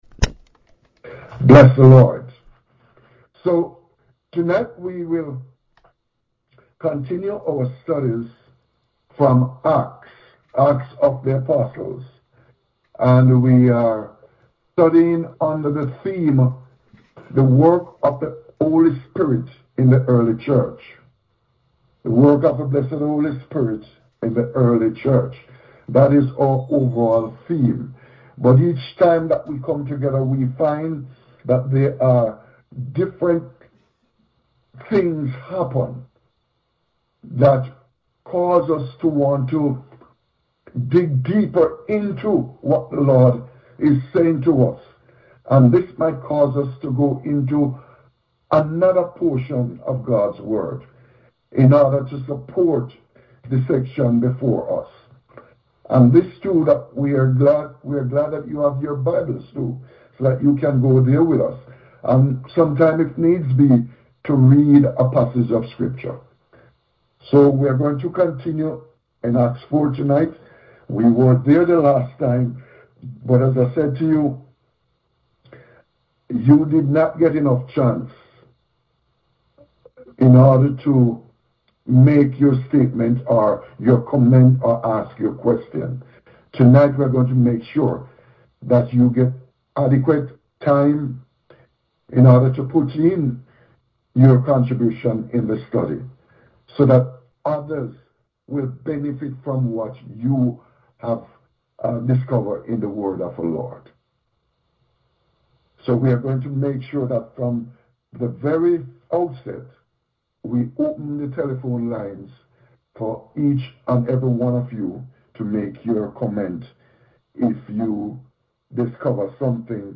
Acts Bible Study